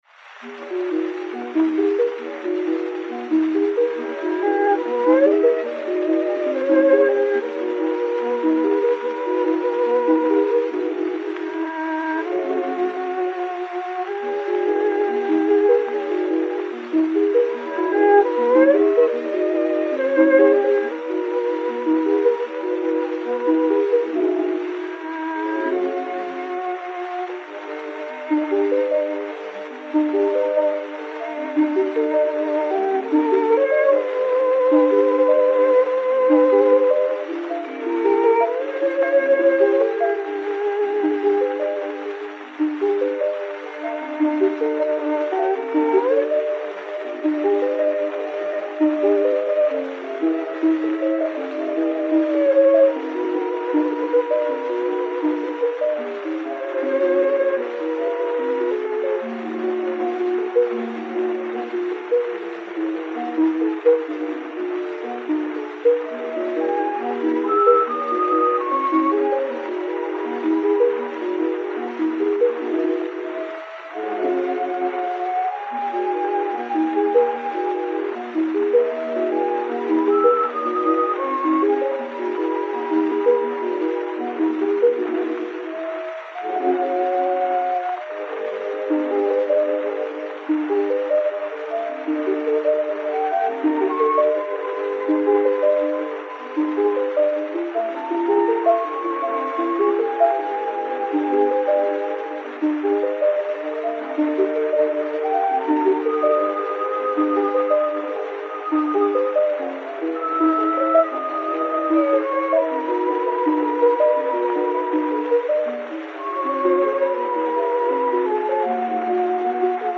Trio Ackroyd (violon, flûte et harpe)